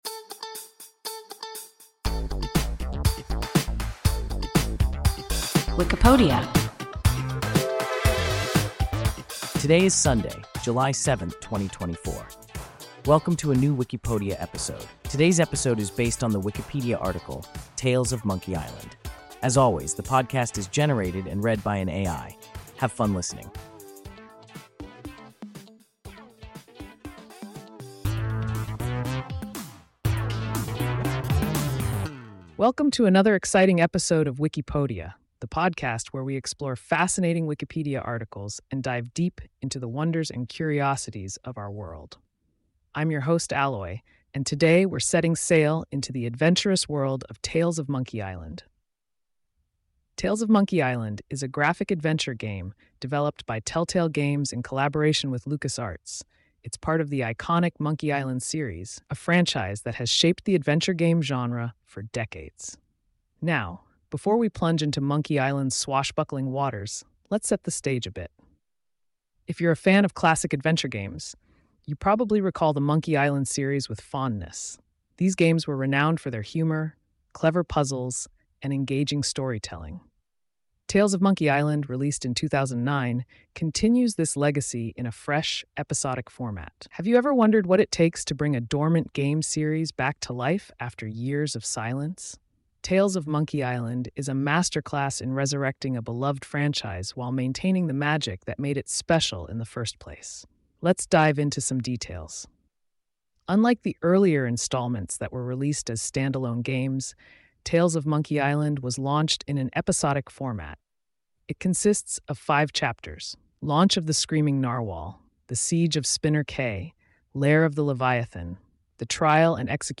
Tales of Monkey Island – WIKIPODIA – ein KI Podcast